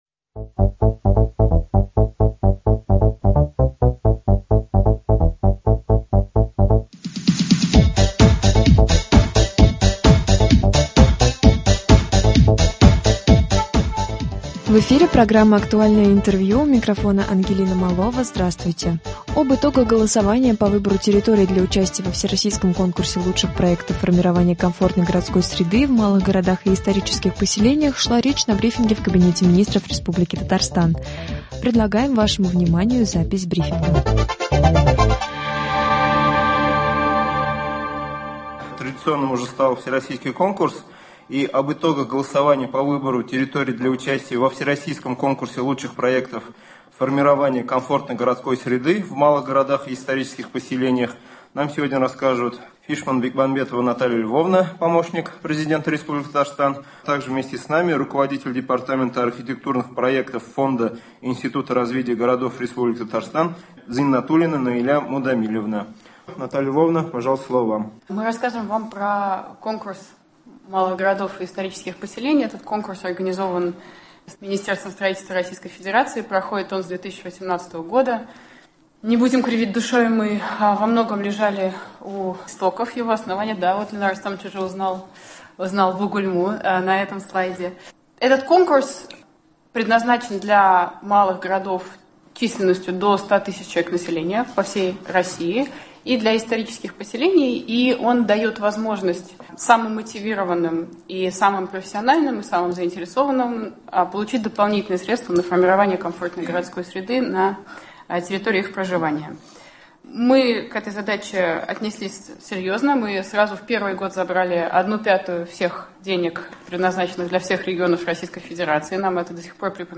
Актуальное интервью (03.03.21)